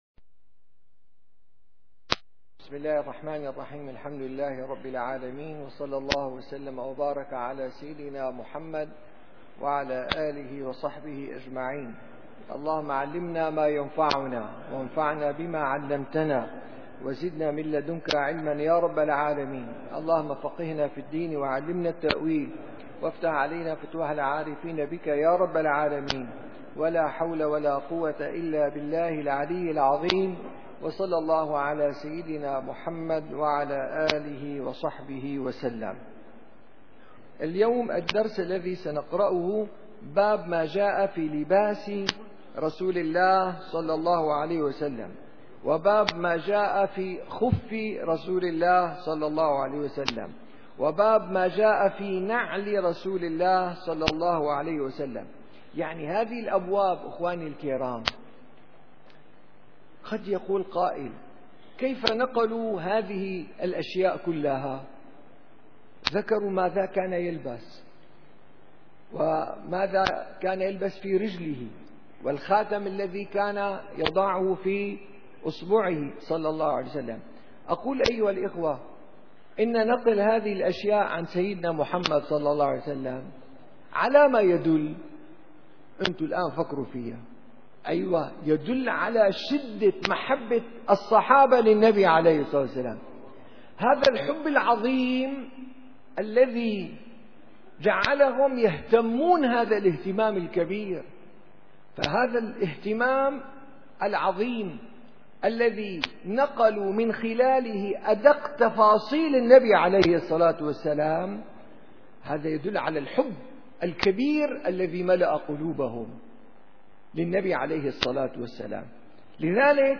الشمائل المحمدية / الدرس الثالث : باب ما جاء في لباس رسول الله و في خُفِّه